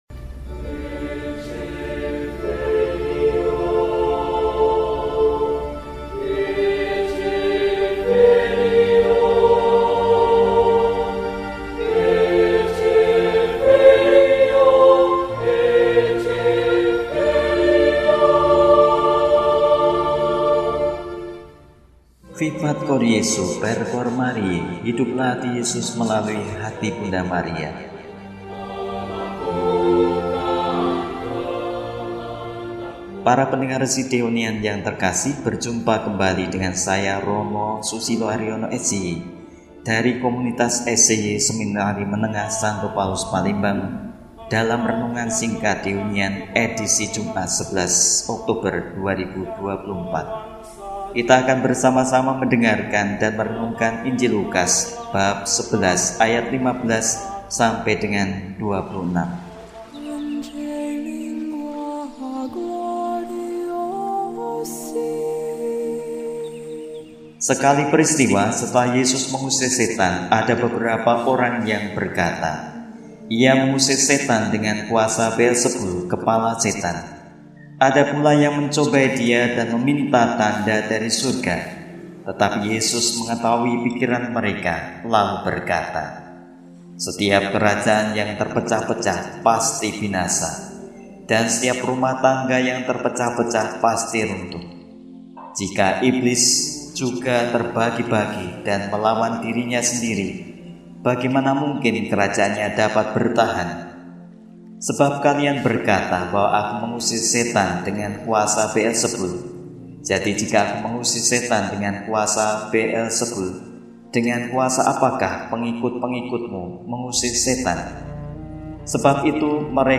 Jumat, 11 Oktober 2024 – Hari Biasa Pekan XXVII – RESI (Renungan Singkat) DEHONIAN